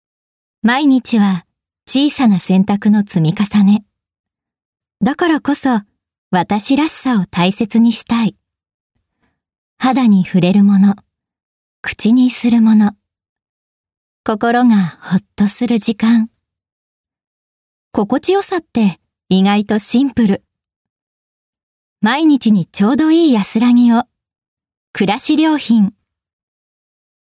↓ここからはボイスサンプルです。
男の子・小学生／それ以下